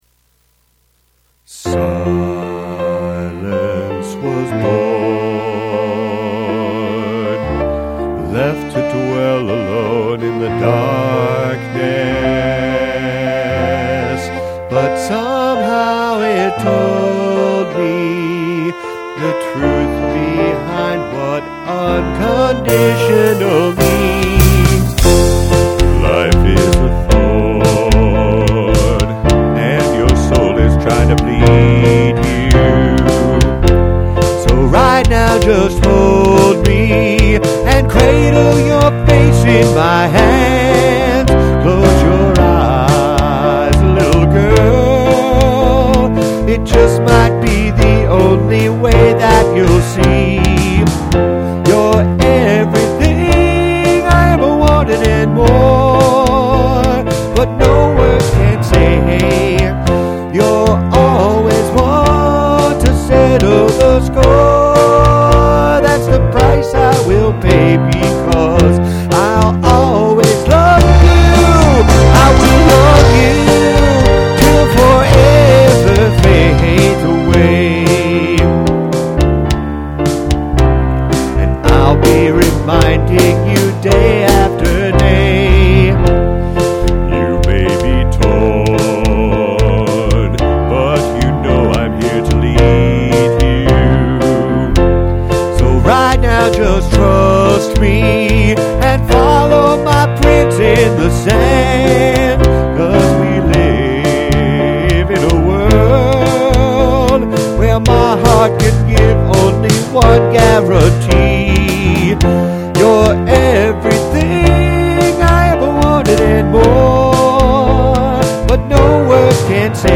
Key of F  –  February, 2002